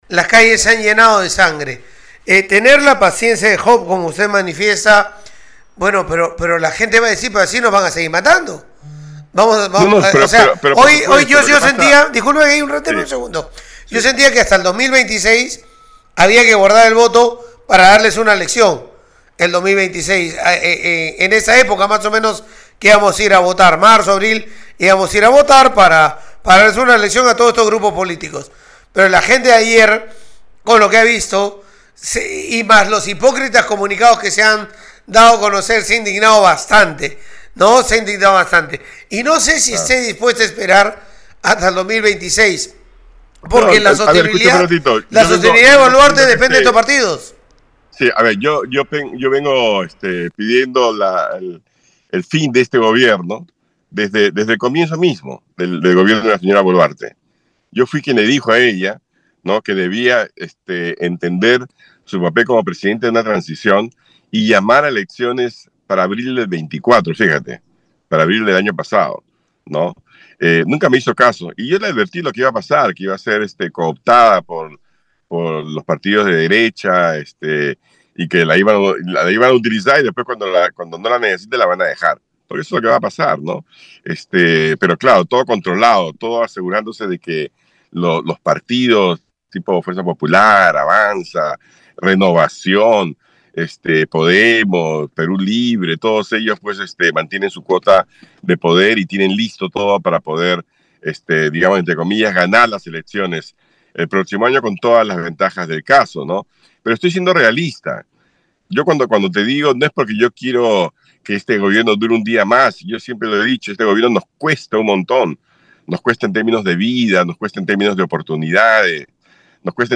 Congresista-carlos-anderson.mp3